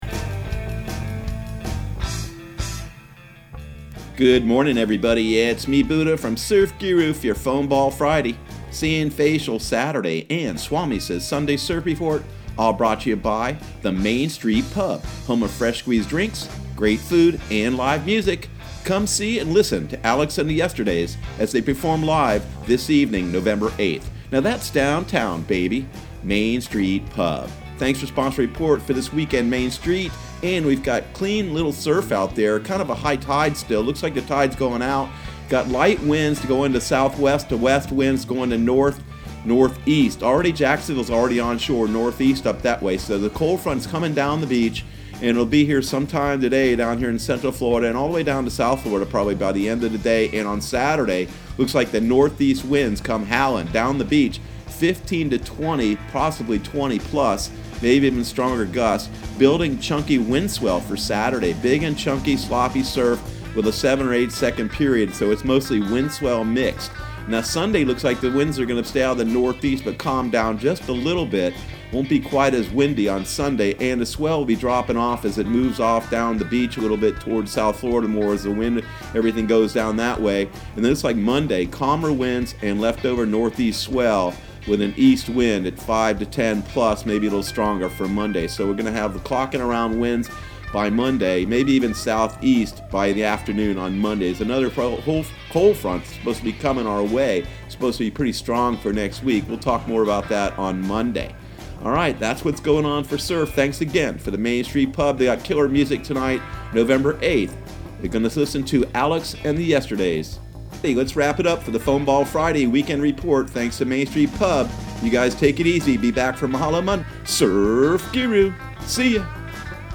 Surf Guru Surf Report and Forecast 11/08/2019 Audio surf report and surf forecast on November 08 for Central Florida and the Southeast.